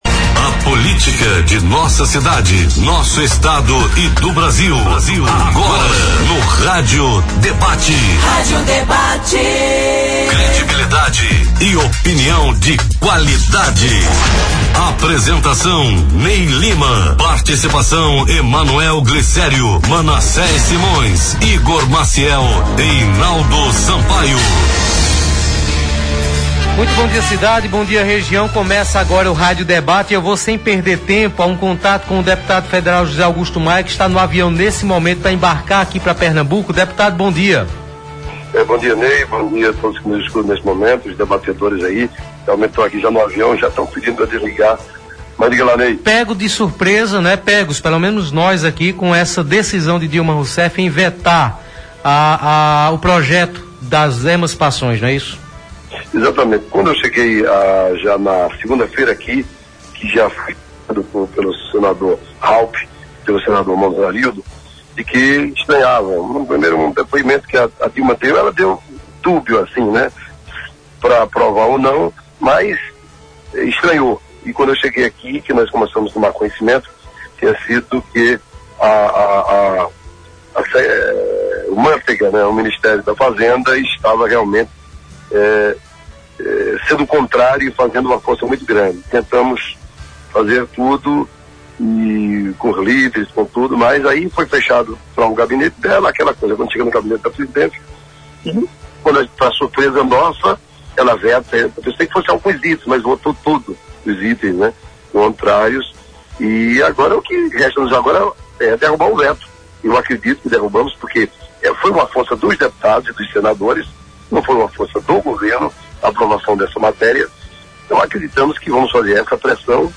O deputado federal José Augusto Maia (PROS) participou por telefone e disse que o governo participou das articulações para aprovação do projeto na Câmara.